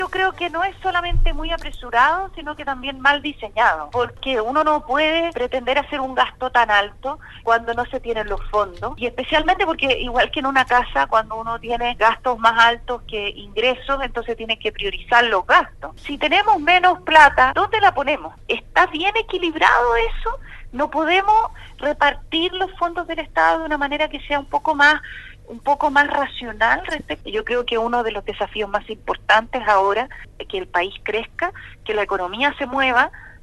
Al respecto, la Senadora por la región de Los Ríos, Ena Von Baer en conversación con Radio Sago, comentó que esto es algo mal diseñado donde cree que cuando no recursos suficientes, debes optar por repartir de manera racional, considerando para ello, por ejemplo las pensiones de los adultos mayores y el Sename.